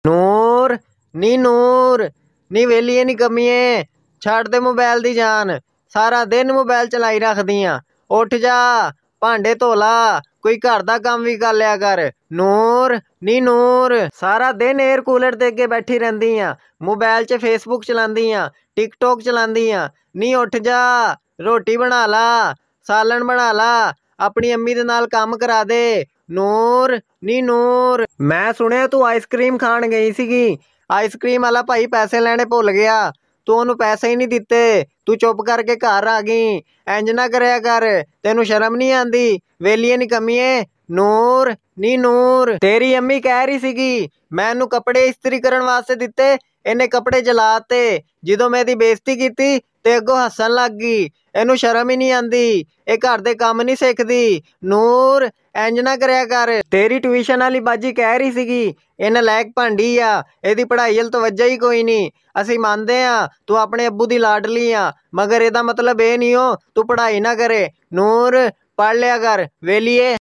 Goat